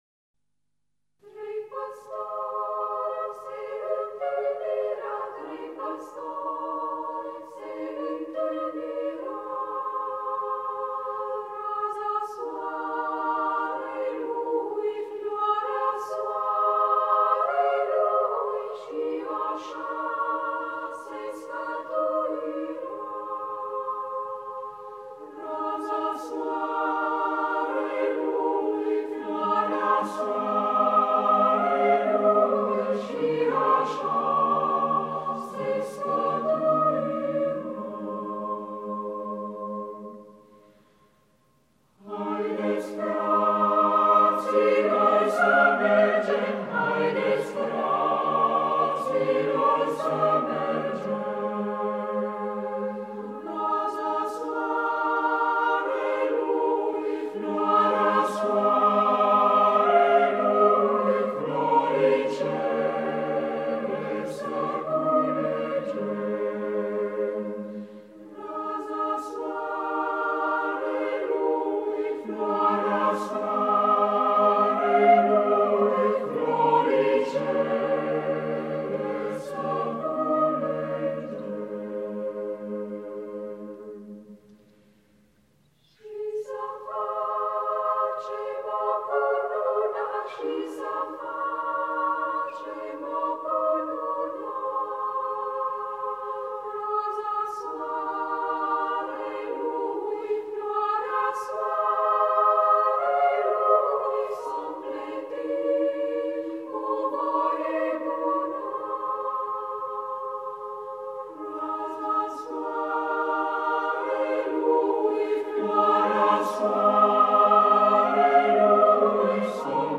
„TREI PĂSTORI” (Timotei Popovici) de pe albumul „BIJUTERII CORALE ROMANESTI (I)” interpretat de Corul Naţional de Cameră MADRIGAL – dirij. MARIN CONSTANTIN.